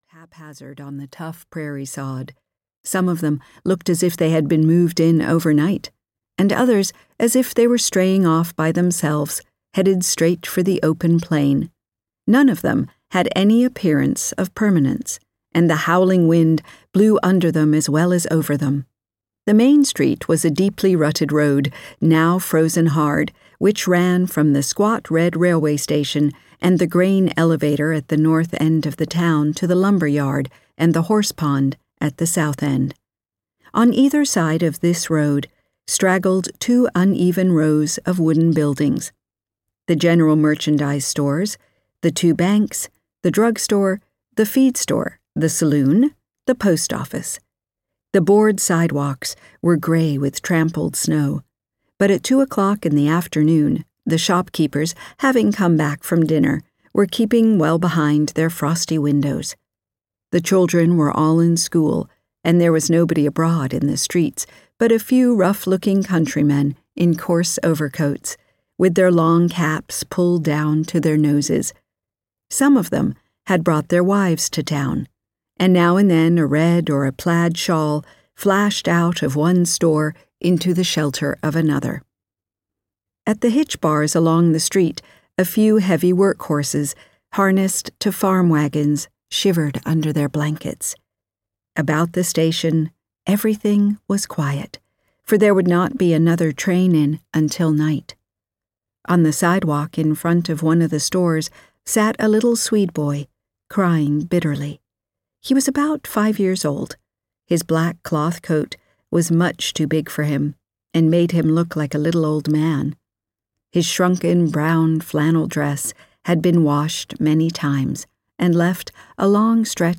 O Pioneers! (EN) audiokniha
Ukázka z knihy